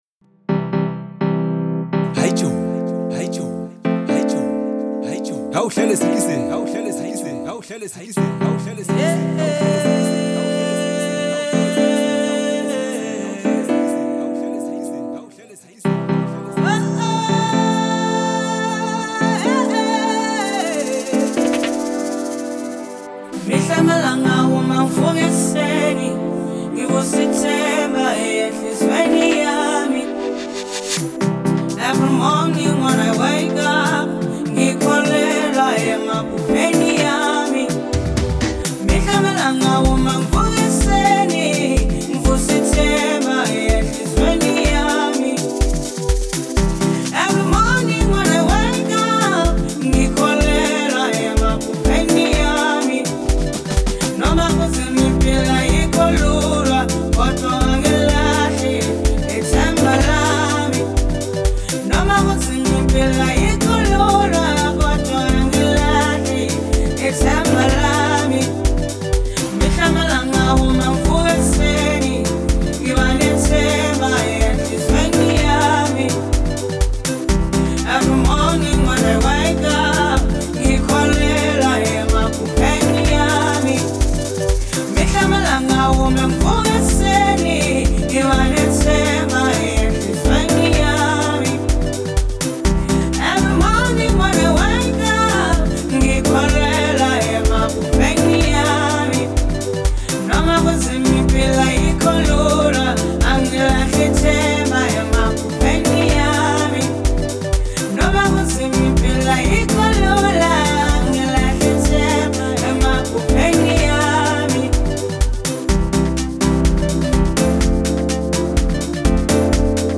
powerful and uplifting House music release
heartfelt House anthem
• Genre: House